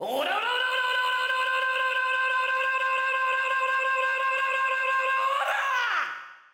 Tusks stand cry
TuskCry.mp3